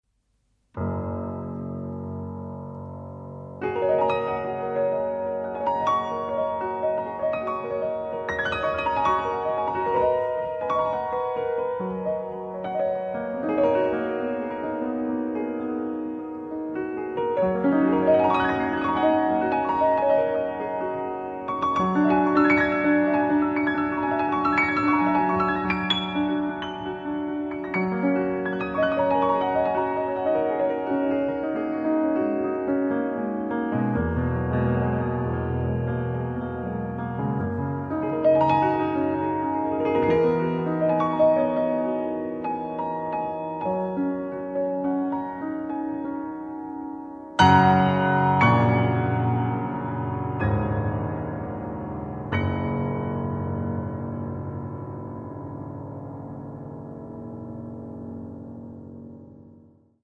tromba, flicorno
sax soprano
pianoforte
contrabbasso
batteria
bandoneon